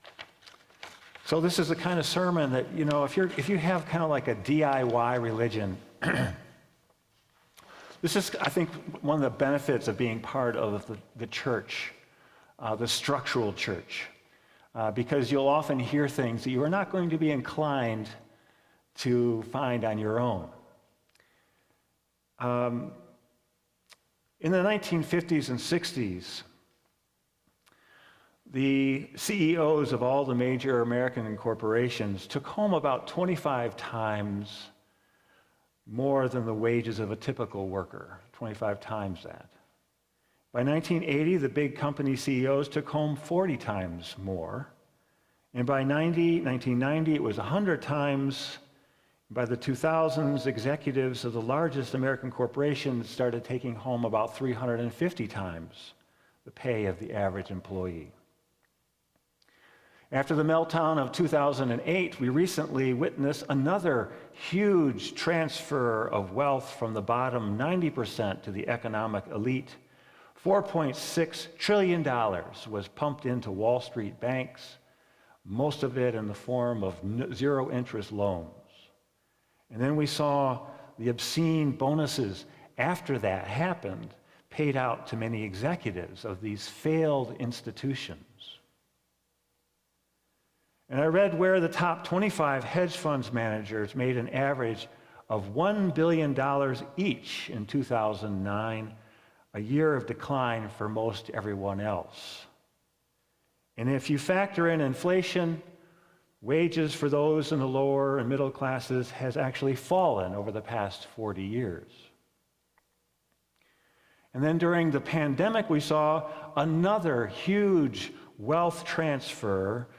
sermon-10-2-22.mp3